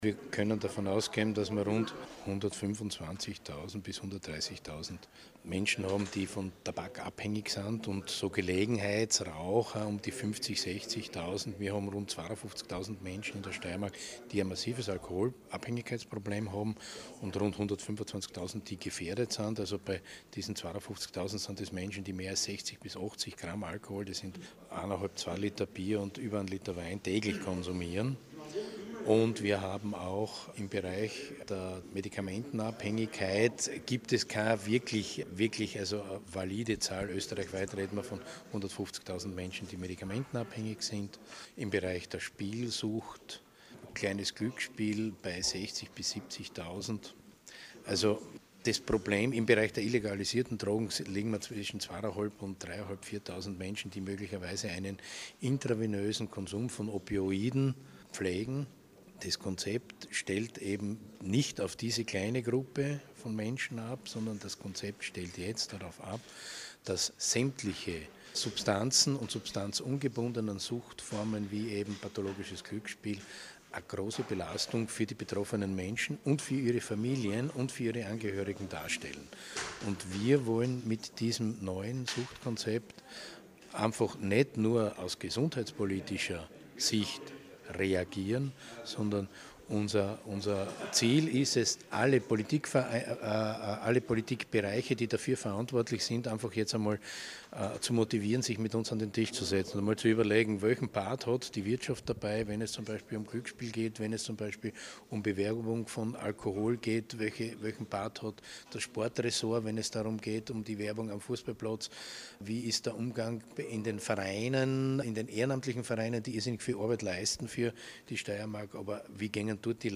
O-Ton: Die neue steirische Suchtpolitik